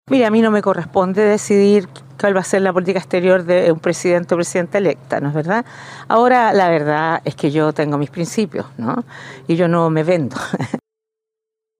La discusión se reactivó luego de que la expresidenta abordara públicamente el tema tras emitir su voto este domingo en el Complejo Educacional de La Reina, en el marco de la segunda vuelta presidencial.